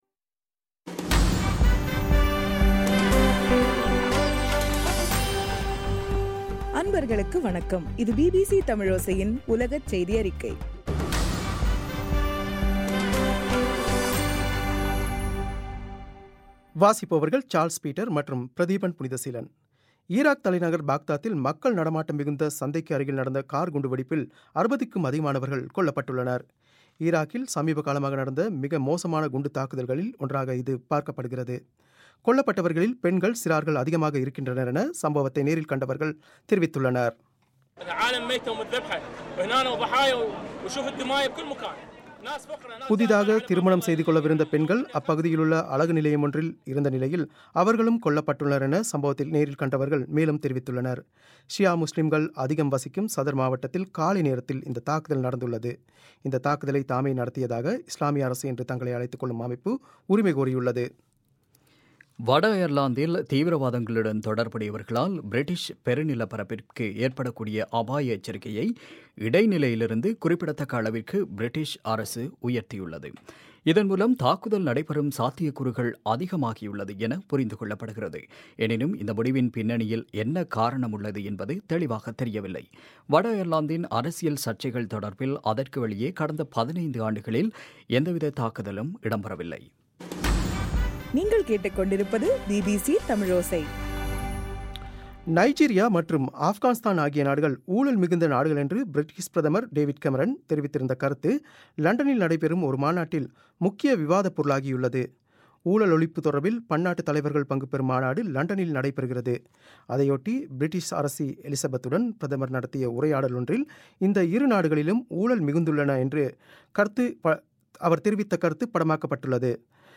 பிபிசி தமிழோசை- உலகச் செய்தியறிக்கை- மே 11